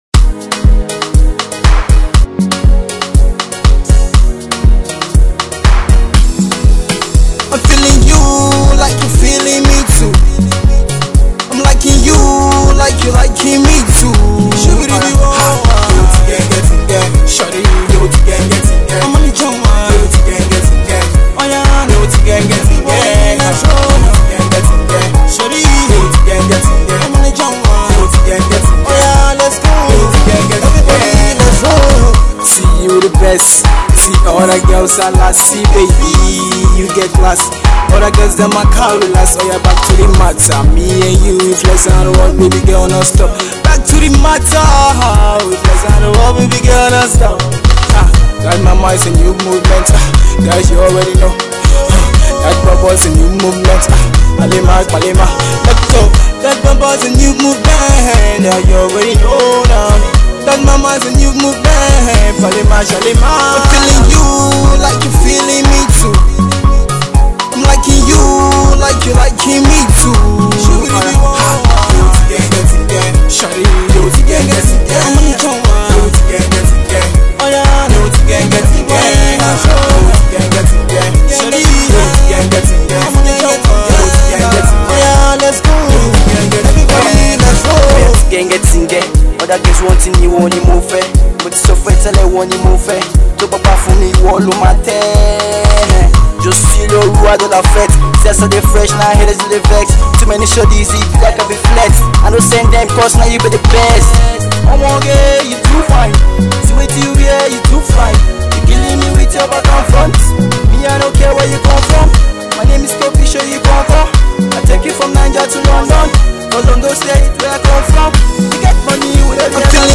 Pop Tune